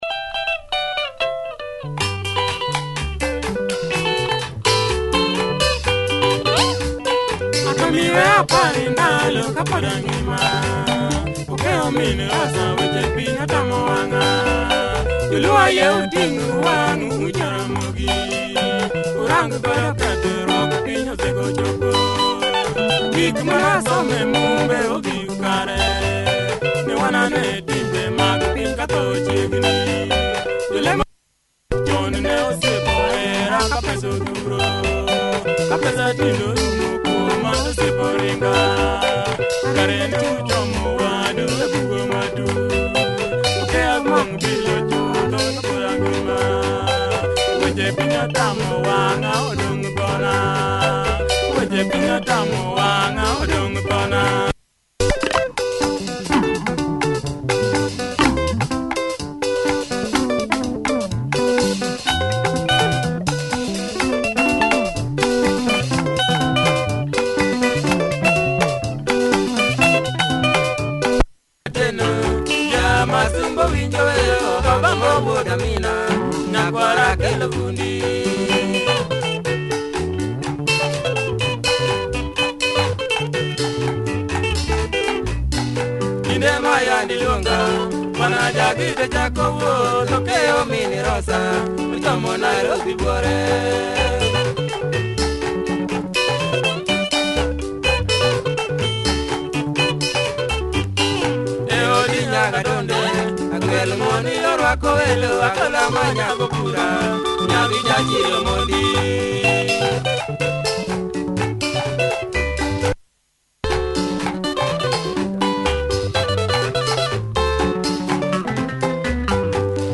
Nice drive in this LUO benga number, good production!